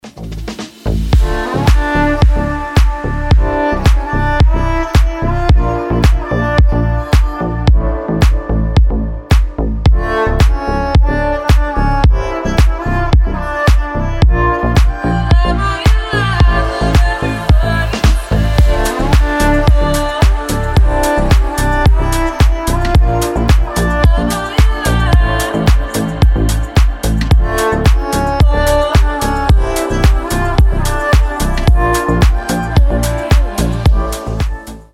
• Качество: 320, Stereo
deep house
восточные мотивы
мелодичные
красивый женский голос